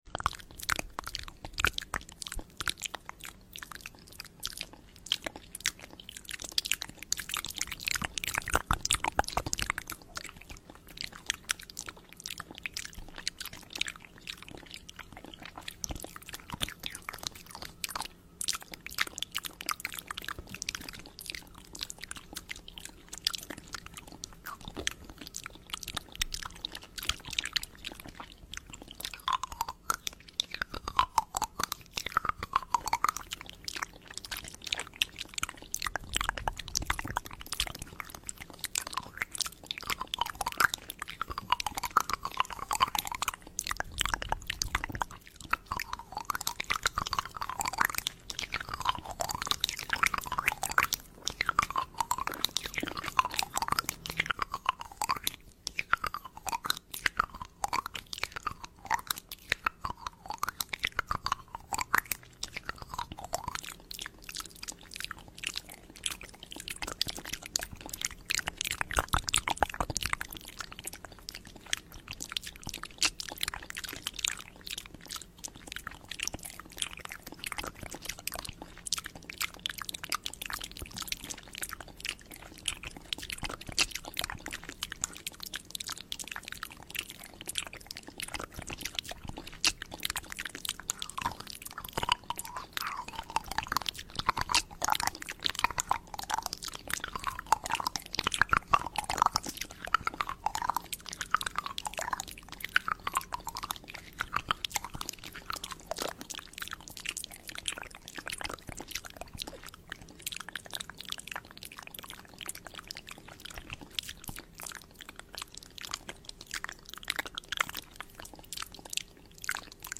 Asmr Eat Jam With A Sound Effects Free Download